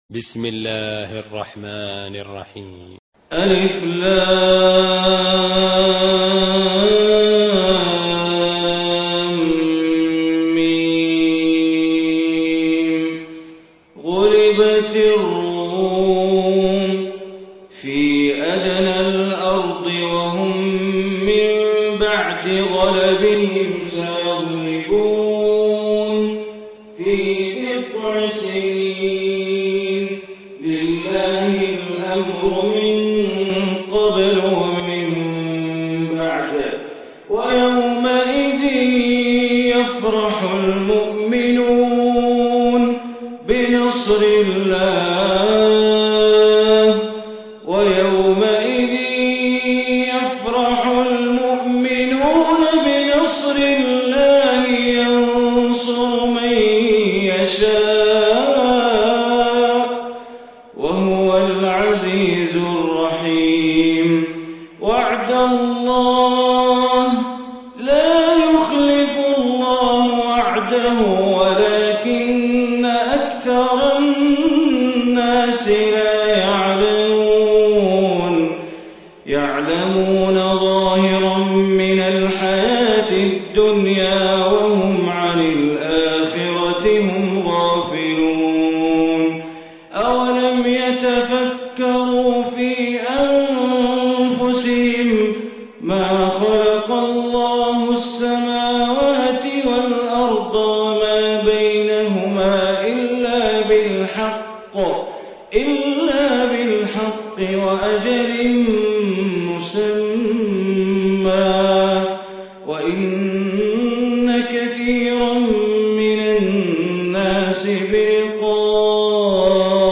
Surah Rum Recitation by Sheikh Bandar Baleela
30-surah-rum.mp3